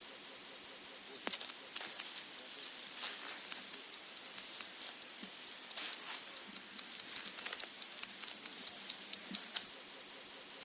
BRYODEMELLA HOLDERERI - АКУСТИЧЕСКИЕ СИГНАЛЫ
Акустические сигналы саранчового
ВНИМАНИЕ ! Синусоидальное изменение амплитуды, особенно заметное на осциллогаммах с разверткой в 10 секунд, обусловлено разноудаленностью летящего самца от микрофона во время записи.
Акустические сигналы: самец в "прямолинейном" полете, Монгольская Народная Республика, Убсу-Нурский аймак, южный берег озера Убсу-Нур, 18.VIII 1995; запись